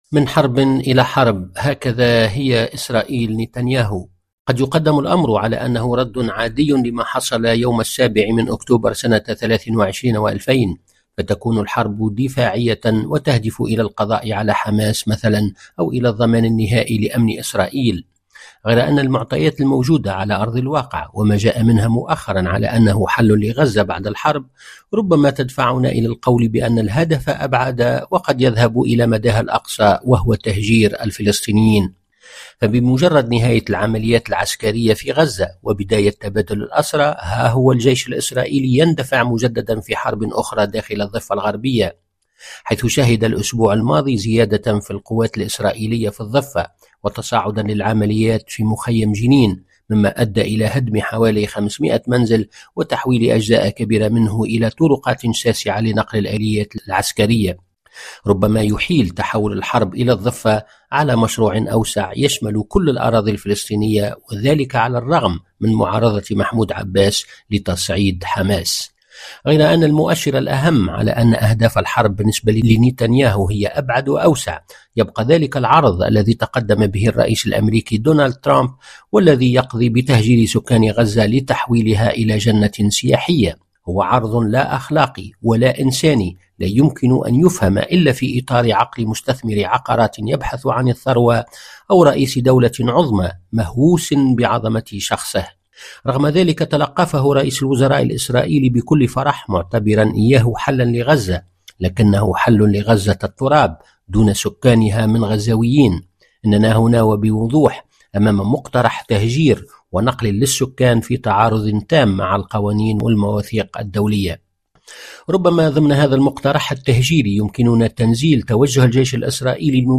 فقرة إخبارية تتناول خبراً أو حدثاً لشرح أبعاده وتداعياته، تُبَثّ على مدار الأسبوع عند الساعة الرابعة والربع صباحاً بتوقيت باريس ويُعاد بثها خلال الفترات الإخبارية الصباحية والمسائية.